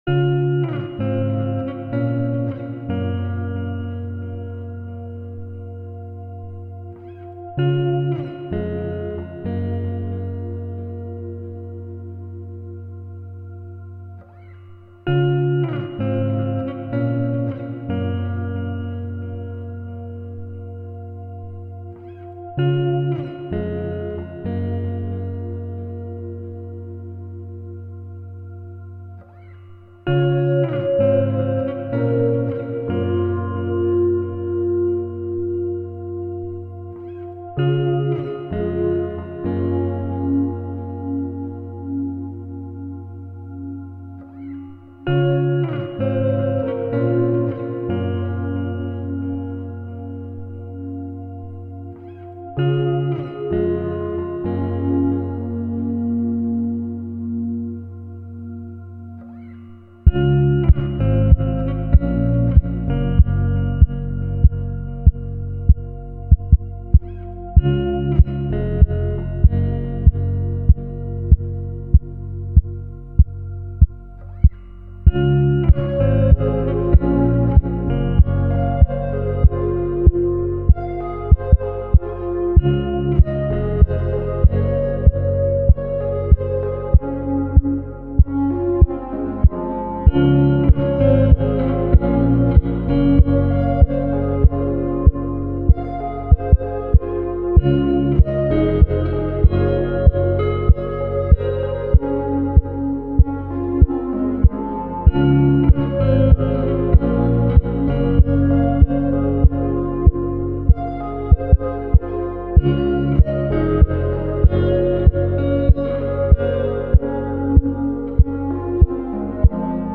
Guitar Blues
موسیقی بیکلام